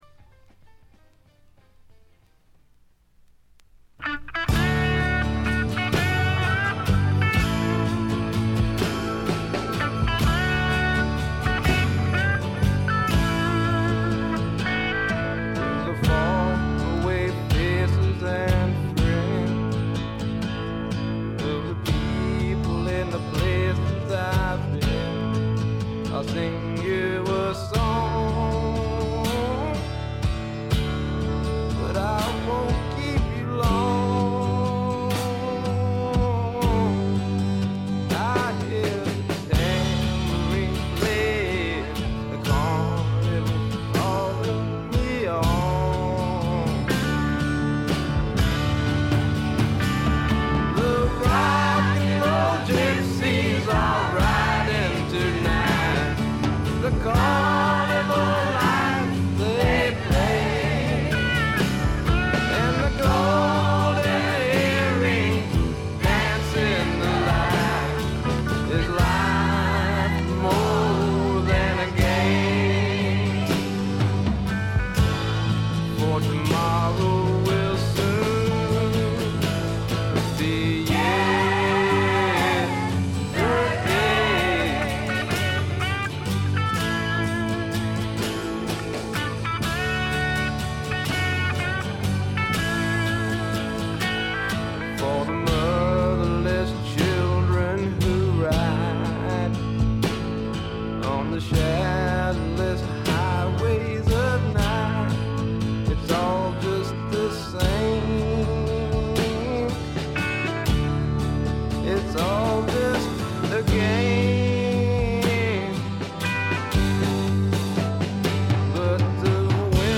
わずかに軽微なチリプチ。
まさしくスワンプロックの真骨頂。
試聴曲は現品からの取り込み音源です。
Vocal, Guitar, Keyboards